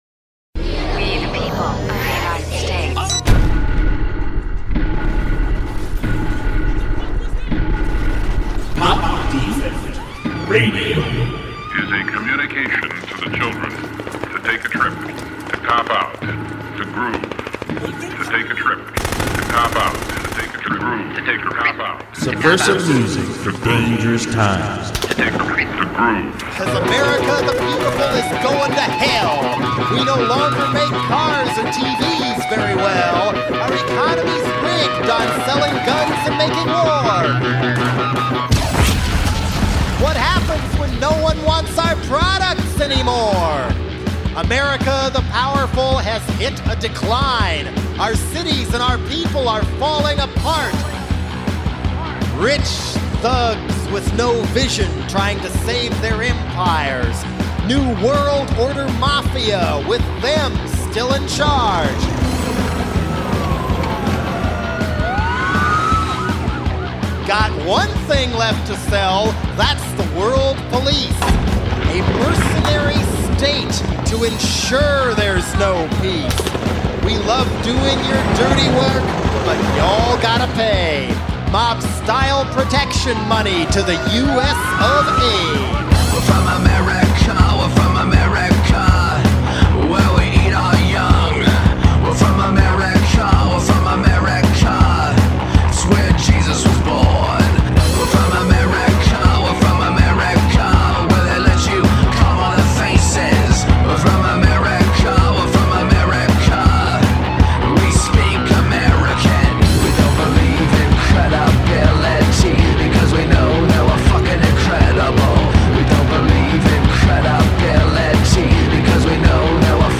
various special mixed in samples from film and media